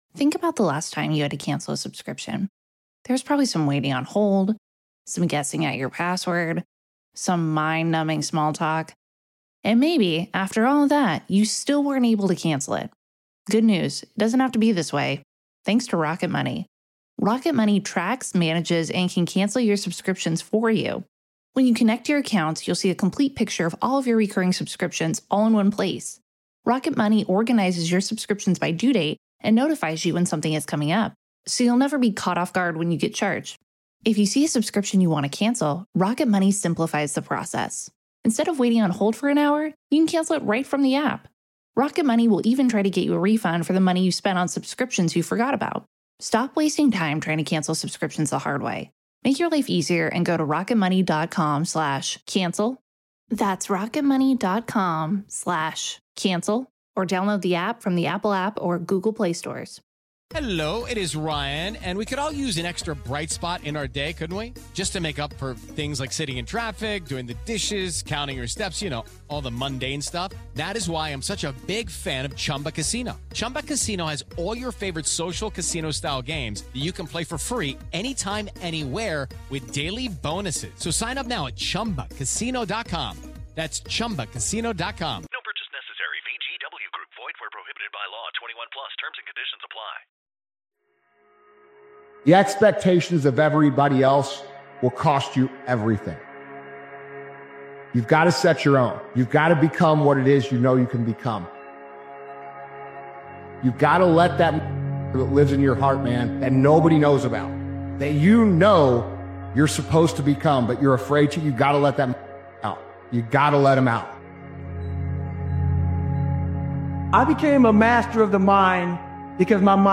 Powerful Motivational Speeches Video is a raw and emotionally charged motivational video created and edited by Daily Motivations. This powerful motivational speeches compilation reminds you that quitting isn’t an option when you’ve already sacrificed, struggled, and pushed this far.